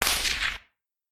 whiz_super_00.bak.ogg